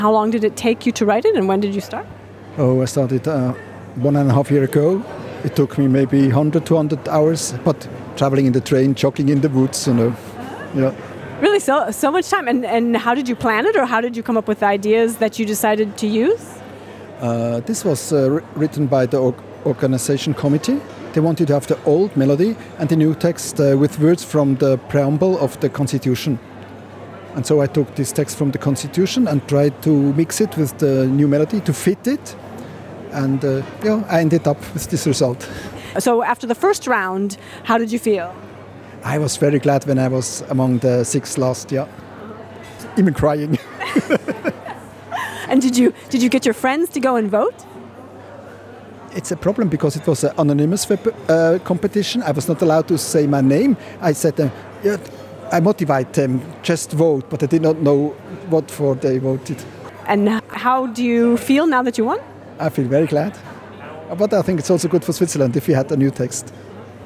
Interview with Anthem contest winner